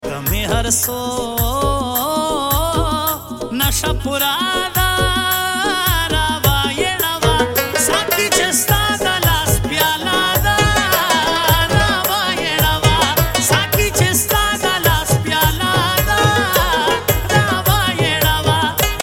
Pashto Mast Sad Tappy